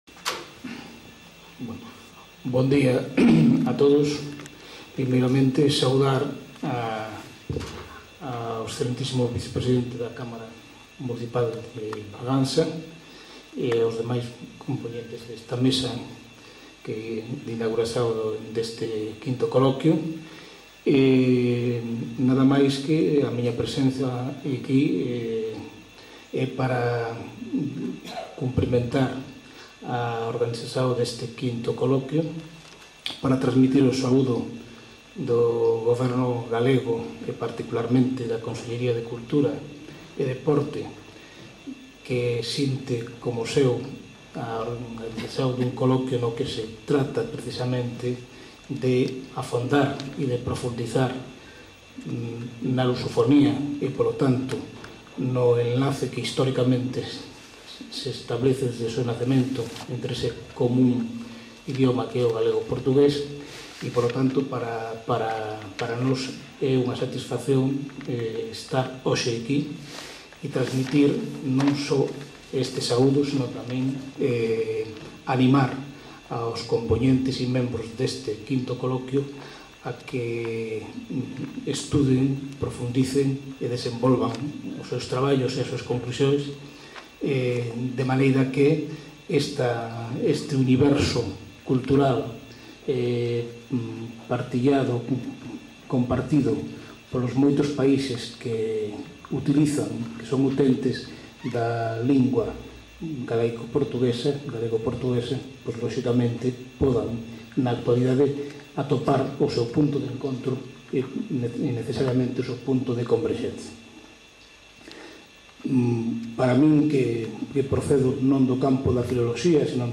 Lugar: Bragança
Orador: Xosé Carlos Sierra, delegado em Ourense da Consellería de Cultura e Deporte da Xunta de Galicia